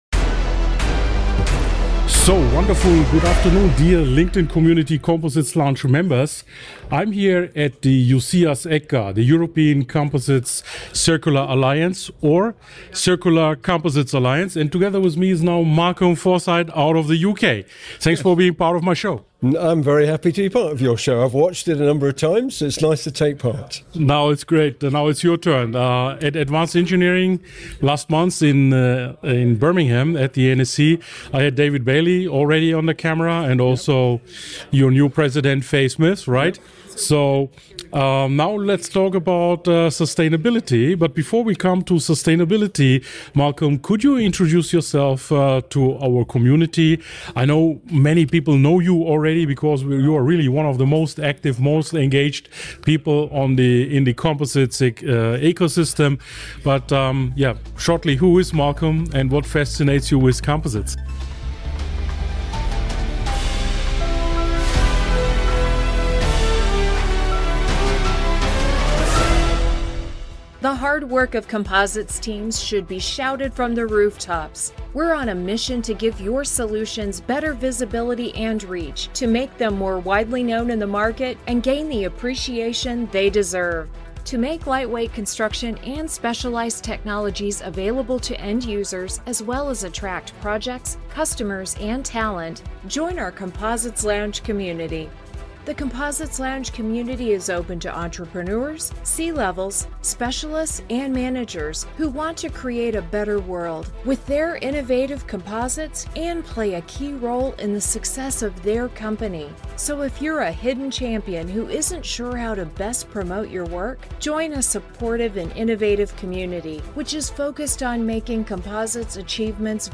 This is an engineering conversation, not a marketing one. We discuss decarbonisation, recycling routes, automotive scale‑up challenges, and why European collaboration through ECCA and The European Composites Industry Association; EuCIA is essential if composites are to become truly circular.